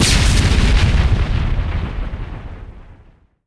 grenadeexplode.ogg